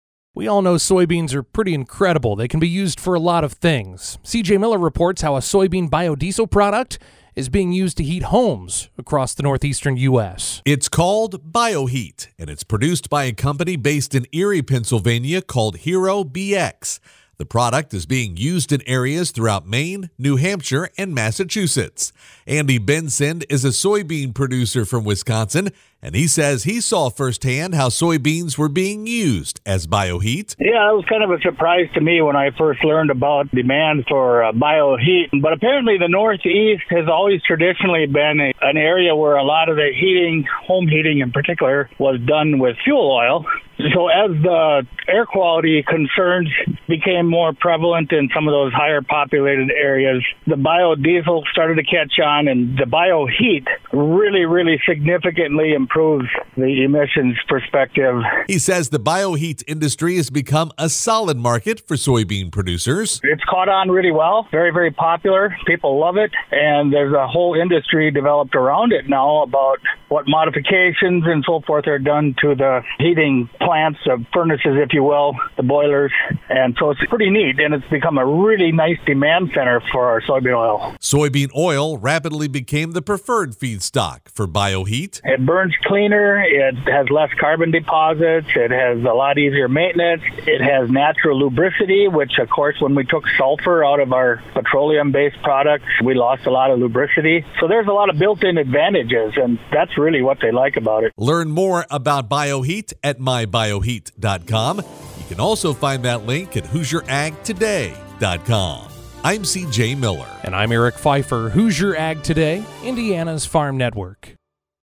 CLICK BELOW to hear Hoosier Ag Today’s radio news report on the use of soybeans to heat homes with Bioheat®.